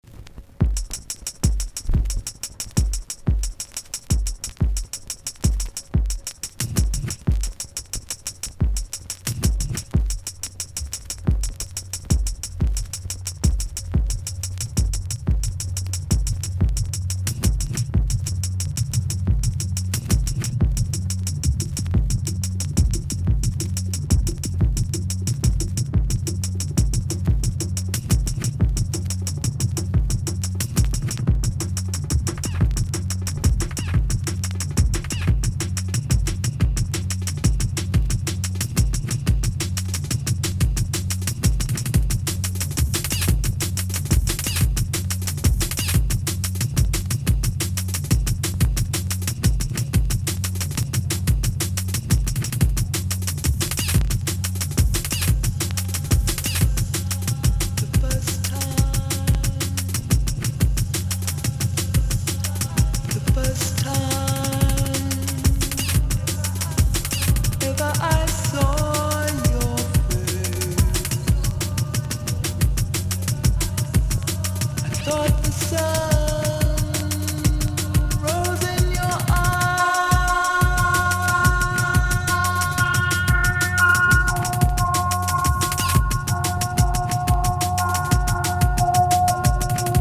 HEADZ / ELECTRONICA / CHILOUT# DUB / LEFTFIELD